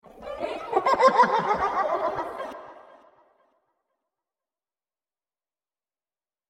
Sound Effects
Weird Laugh